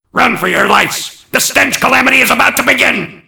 mvm_bomb_alerts08.mp3